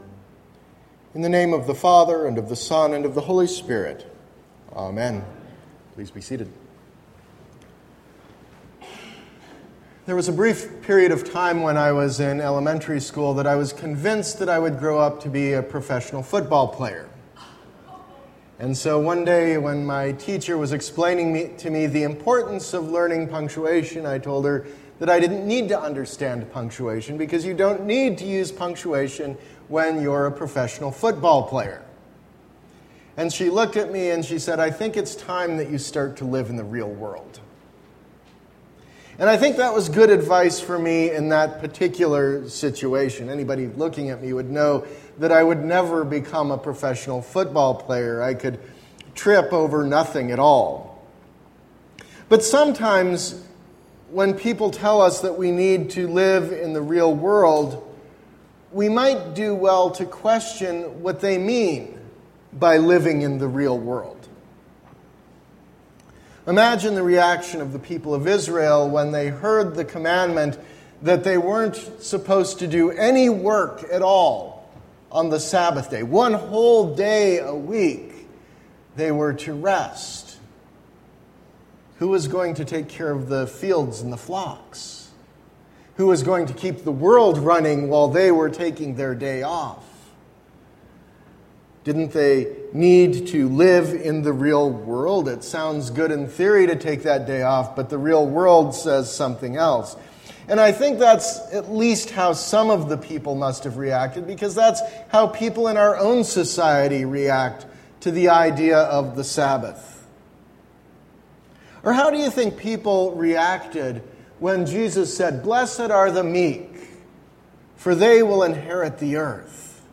Sermon – August 7, 2016